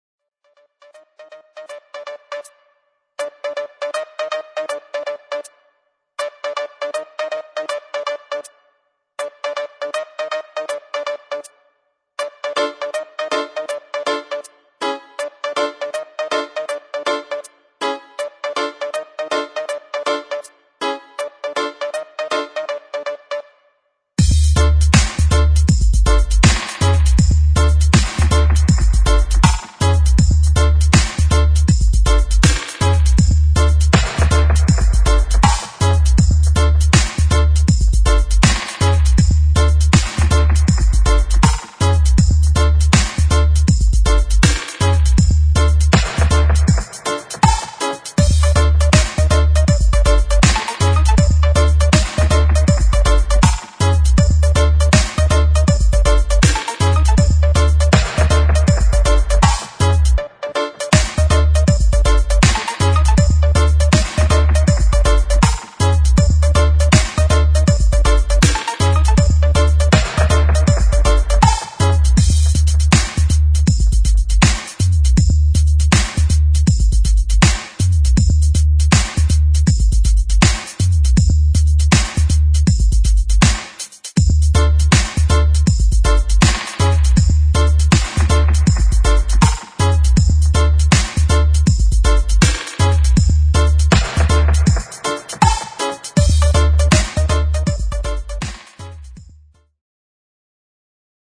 [ REGGAE / DUB ]